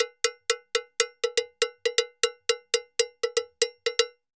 Campana_Salsa 120_2.wav